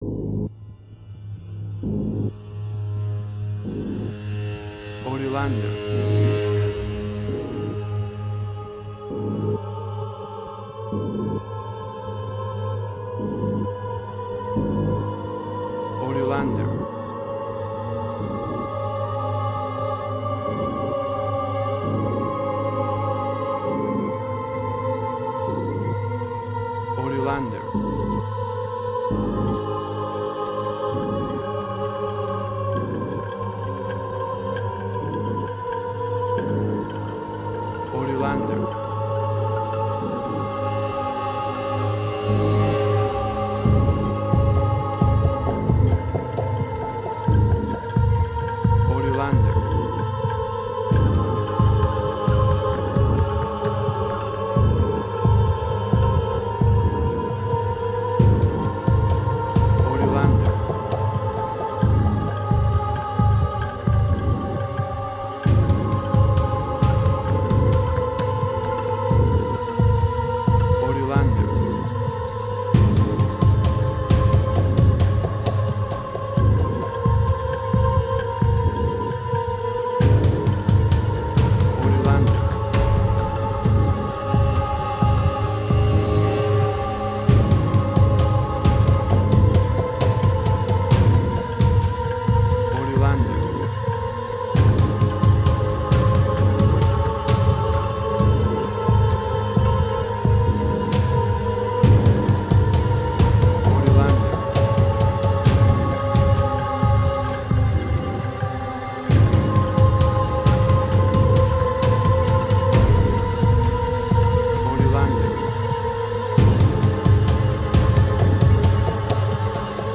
Dissonance
emotional music
Tempo (BPM): 66